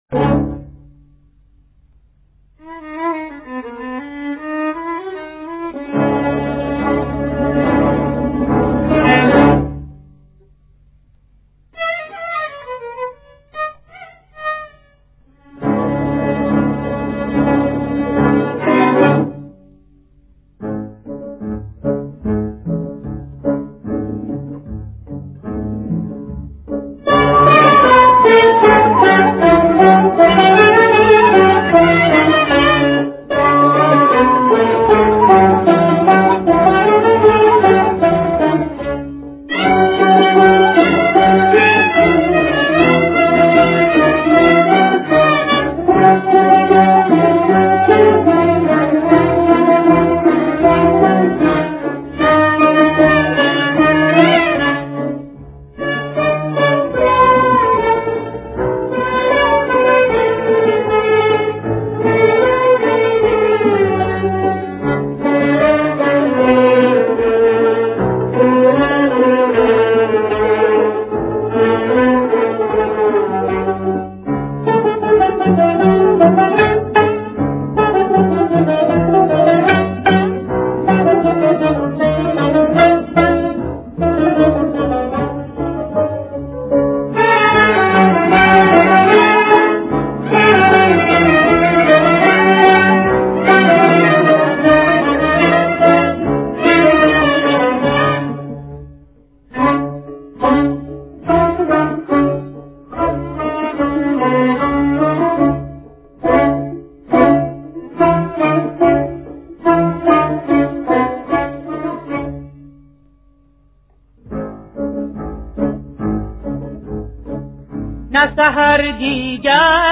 در مقام: شور